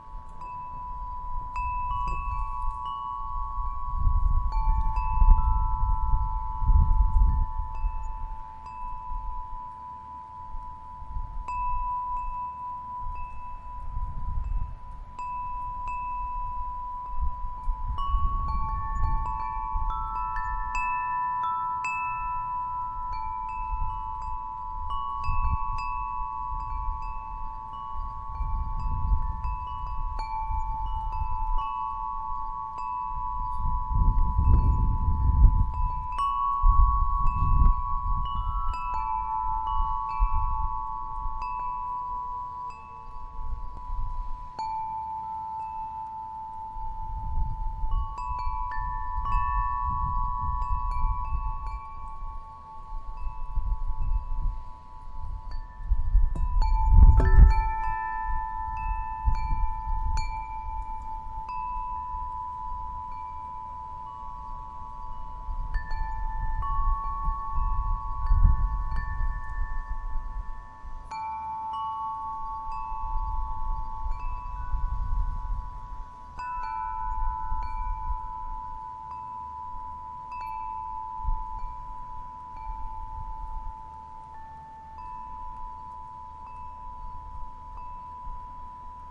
小风铃
描述：我在Zoom H6上用中风录制了我的小风铃
Tag: 风中 风编钟 编钟